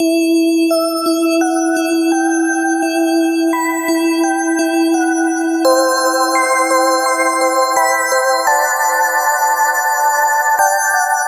描述：静态的，冷静的，环境的。
Tag: 85 bpm Hip Hop Loops Bells Loops 972.84 KB wav Key : E